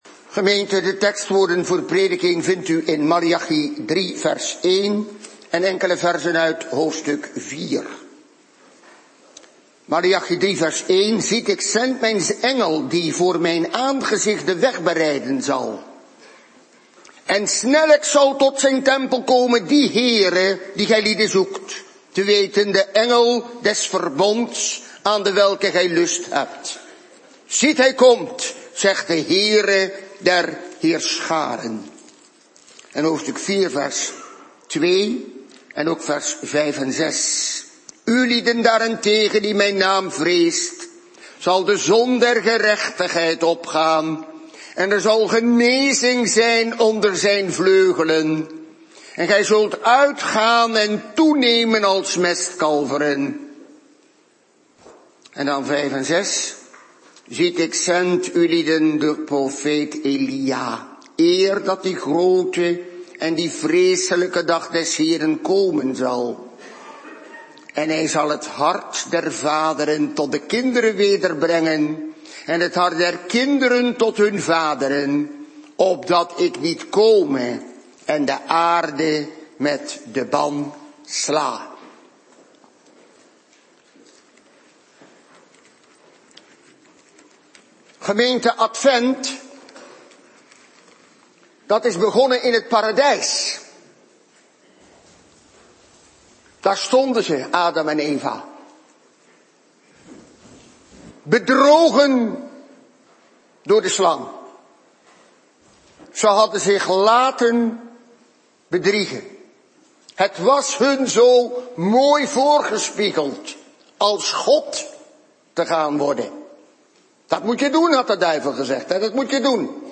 "De Zon van Gerechtigheid" 1. De vergissing van onze tijd 2. Het komende oordeel 3. De komende ontferming, genezing en gerechtigheid 4. De noodzaak van bekering Predikant